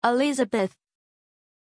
Pronunciation of Elizabeth
pronunciation-elizabeth-zh.mp3